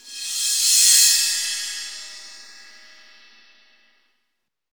Index of /90_sSampleCDs/Roland LCDP03 Orchestral Perc/CYM_Cymbal FX/CYM_Scrapes